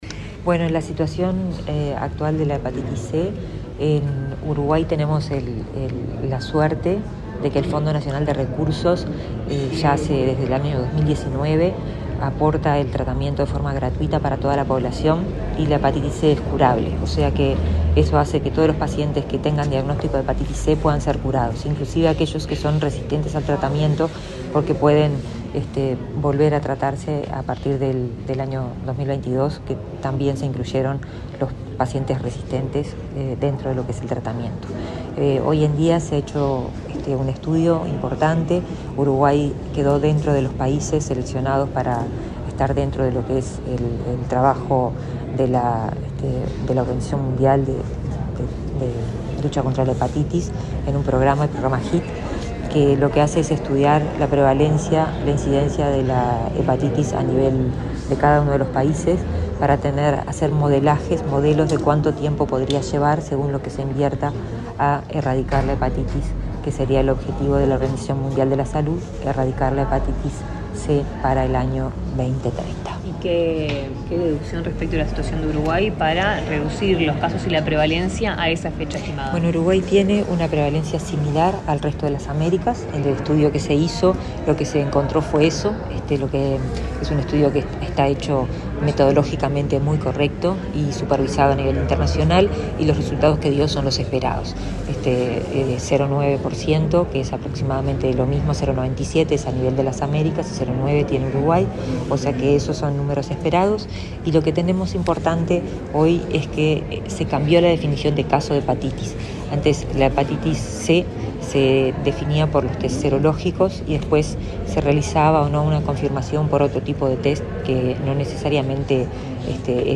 Declaraciones de la ministra de Salud Pública, Karina Rando | Presidencia Uruguay
Este viernes 28, en el Ministerio de Salud Pública, la titular de la cartera, Karina Rando, dialogó con la prensa, luego de presidir un acto por el